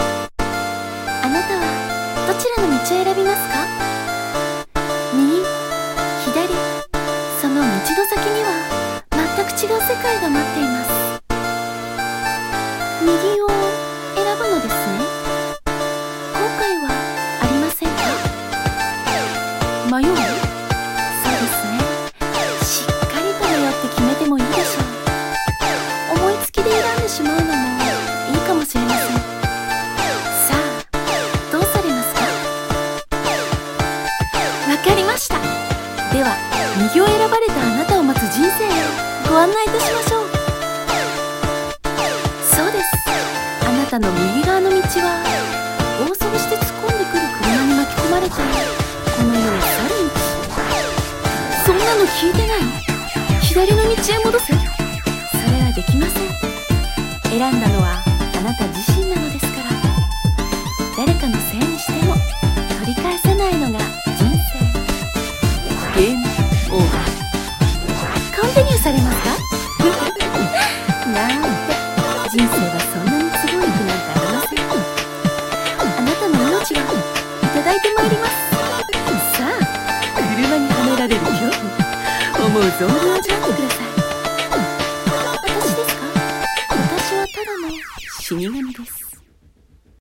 RPG LIFE 朗読台本